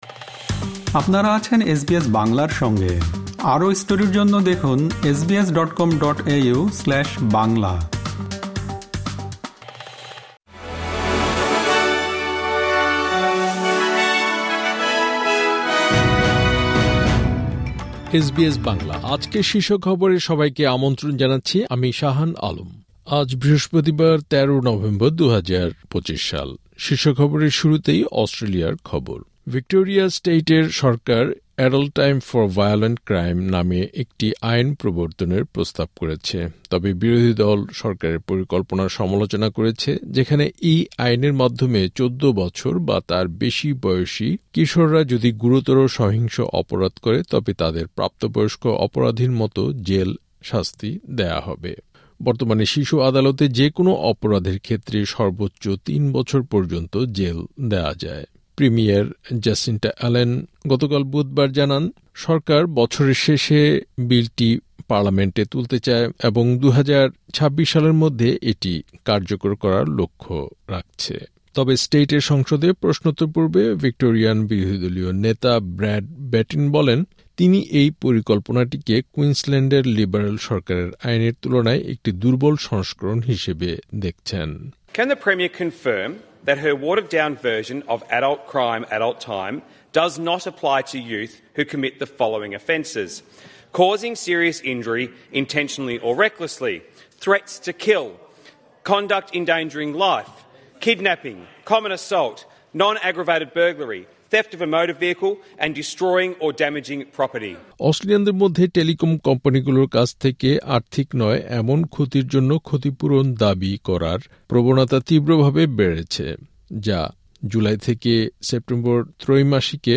এসবিএস বাংলা শীর্ষ খবর: ১৩ নভেম্বর, ২০২৫